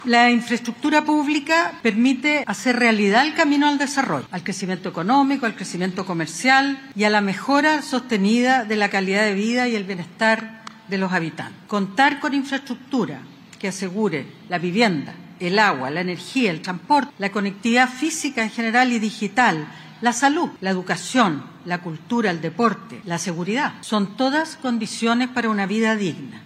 En la actividad, realizada junto a ministros de Estado, autoridades policiales y regionales, se destacó que el plan busca enfrentar los desafíos de conectividad, habitabilidad, seguridad hídrica y energética, además de garantizar condiciones dignas de vivienda, transporte, salud, educación, cultura y seguridad en todo el país.
La ministra de Obras Públicas, Jessica López, subrayó que este plan “nos entrega una visión común que proyecta un futuro para todos, recogiendo los proyectos actuales y las obras que deberemos acometer para el Chile del 2055”.